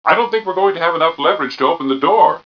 1 channel
mission_voice_t6gk015.wav